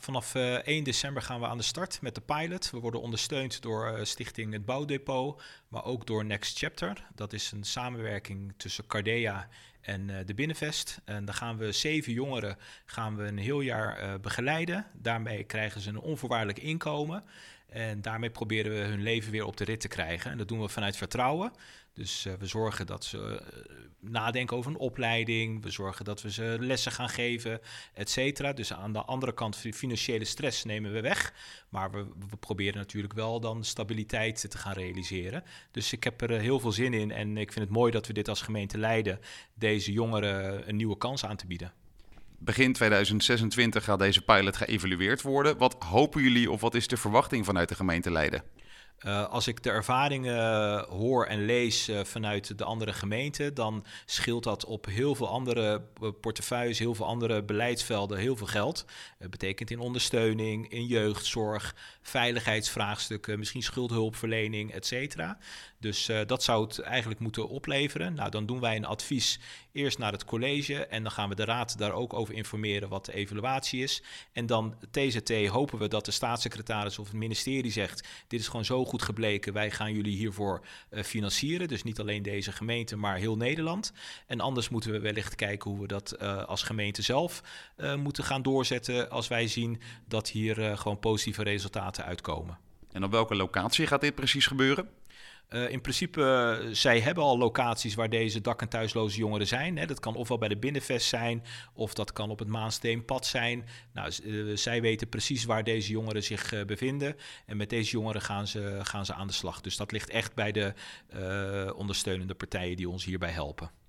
Wethouder Abdelhaq Jermoumi vertelt over de Pilot
Interview Leiden Maatschappij Politiek